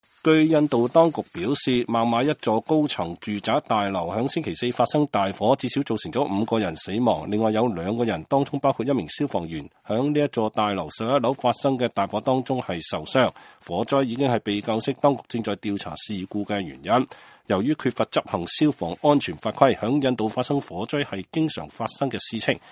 2018-12-28 美國之音視頻新聞: 孟買住宅大樓大火造成5人喪生